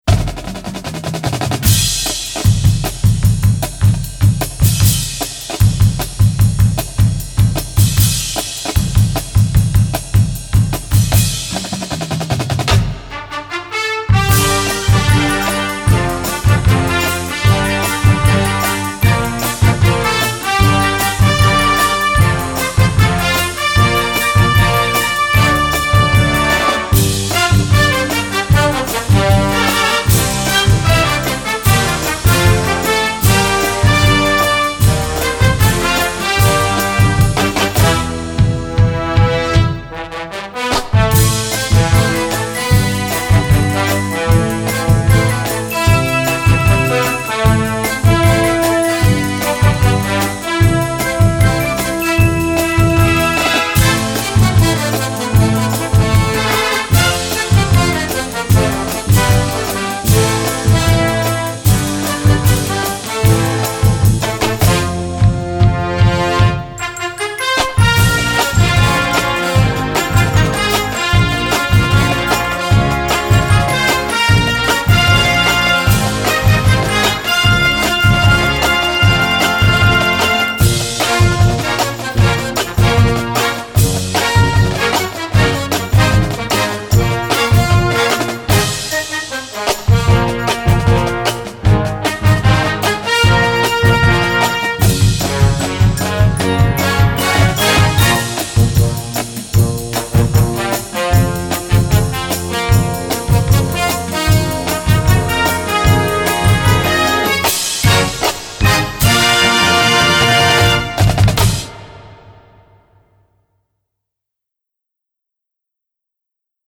Gattung: Filmmusik
Marching-Band
Besetzung: Blasorchester